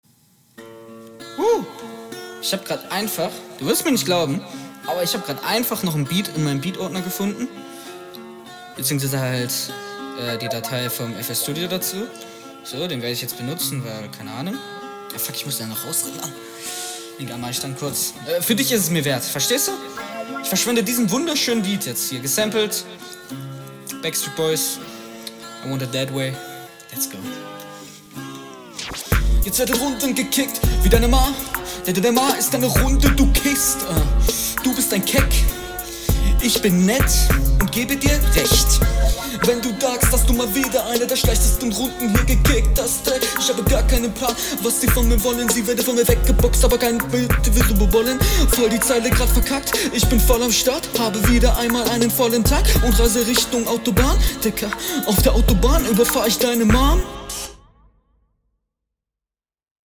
Flow: ➨ Dafür dass das Freestyle ist, ist das schon sehr offbeat.
Flow: Du rappst auf dem Takt und betonst auch ganz solide.